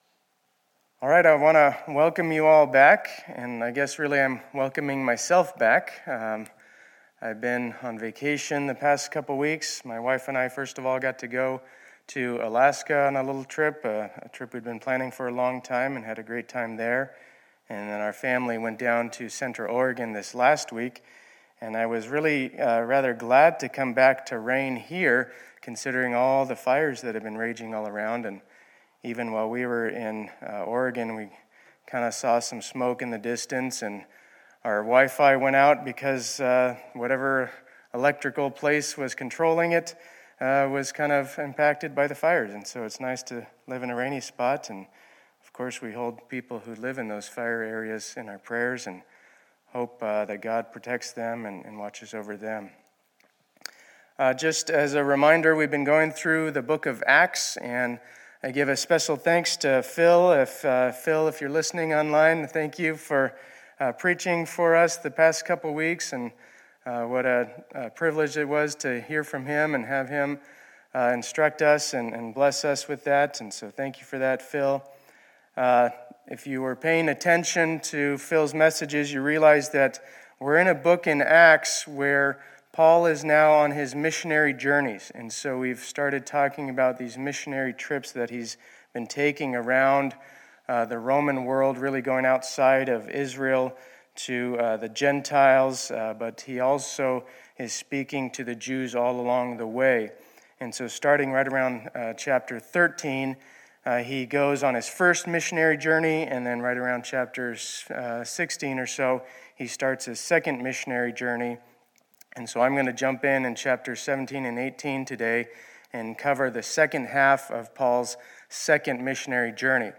2020-08-23 Sunday Service